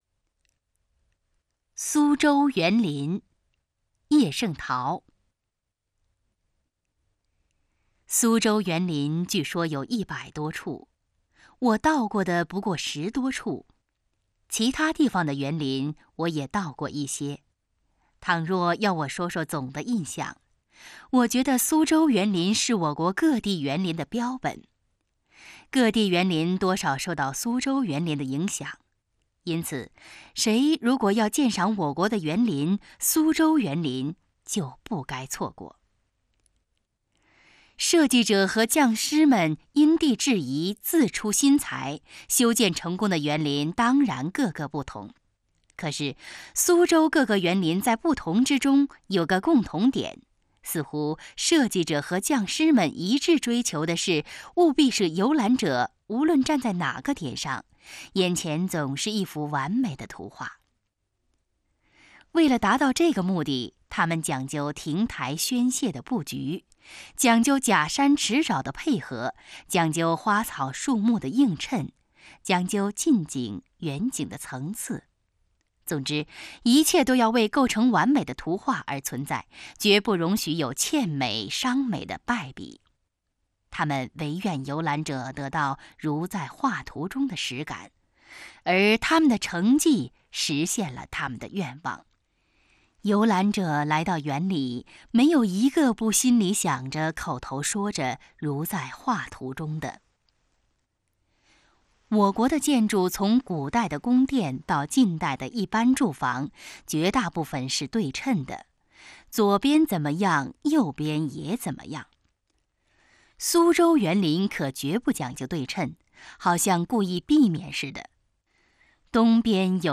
《苏州园林》mp3朗读